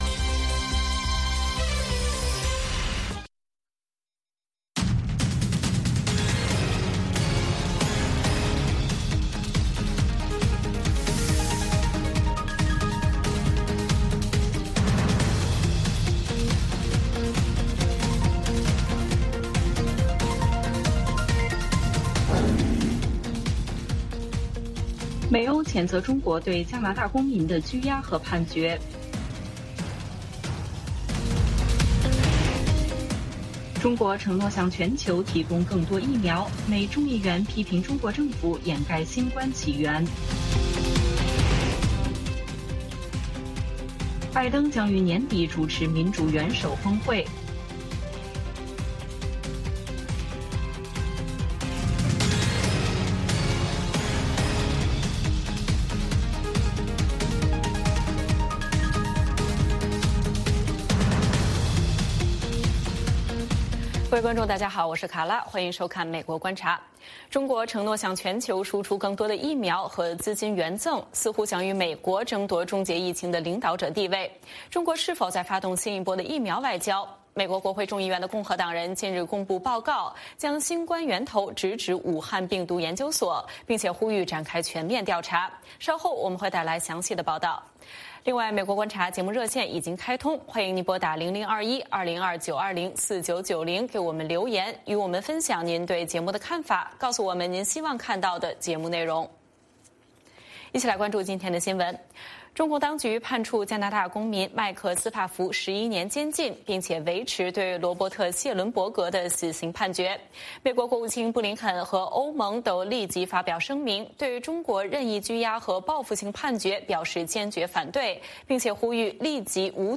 美国国会众议院外交事务委员会共和党领袖麦考尔众议员接受美国之音专访，谈及新冠病毒起源调查报告更新内容，指责中国政府在新冠病毒大流行病问题上进行各种掩盖。